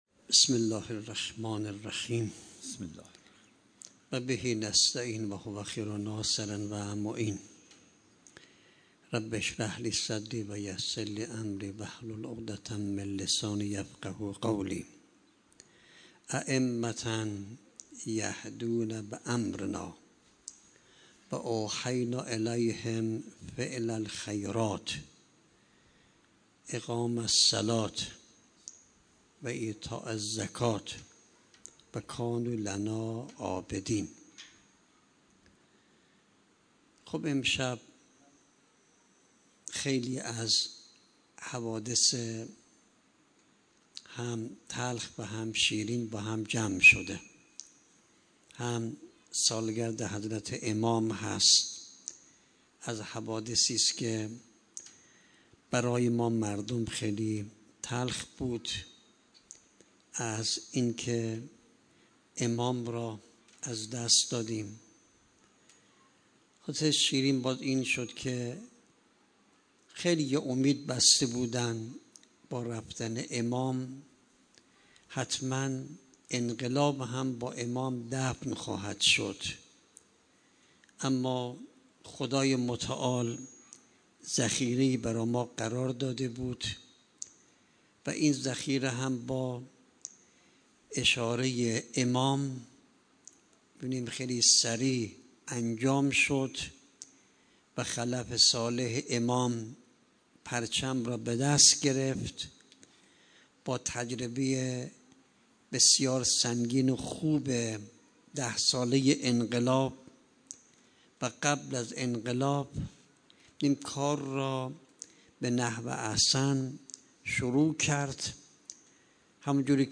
گزارش صوتی دویست و هفتاد و سومین کرسی تلاوت و تفسیر قرآن کریم - پایگاه اطلاع رسانی ضیافت نور